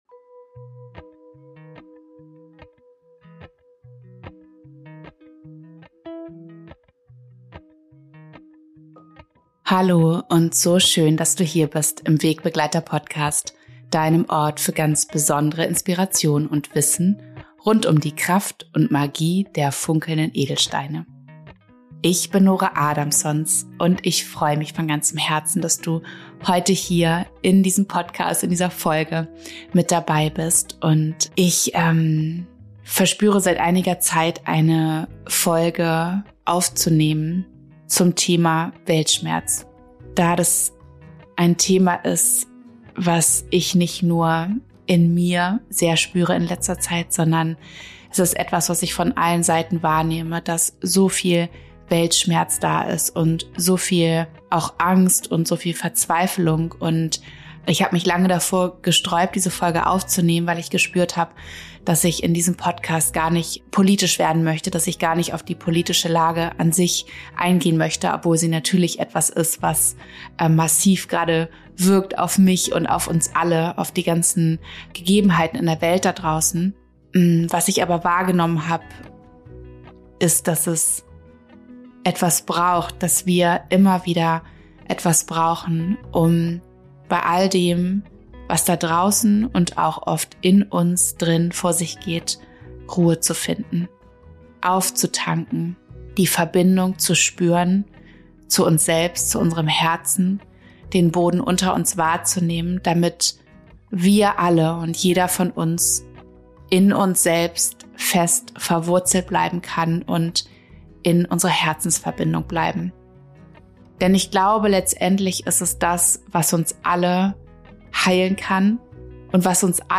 Spüre deinen inneren Frieden – Deine geführte Meditation gegen Weltschmerz ~ WEGBEGLEITER Podcast